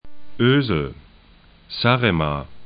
Ösel 'ø:zl Saaremaa 'sa:ɛma: et [hist.]